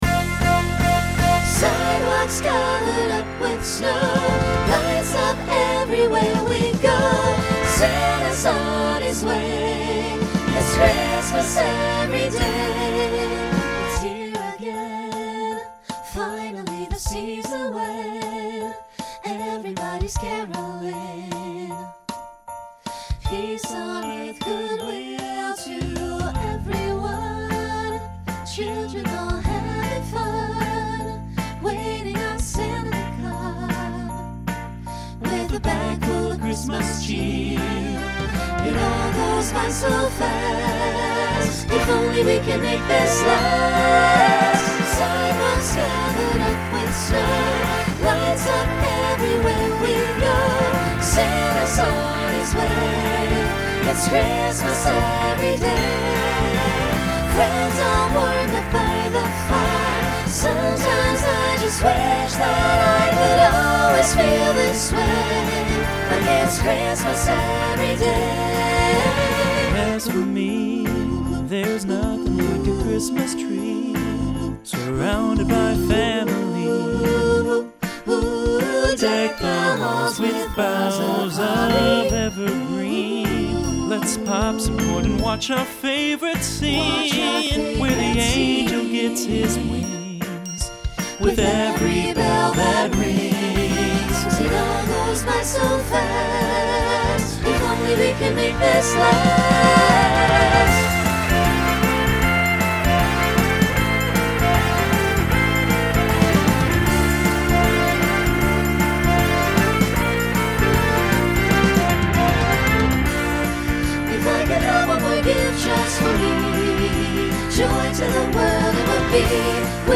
Genre Holiday , Pop/Dance , Swing/Jazz
Voicing SATB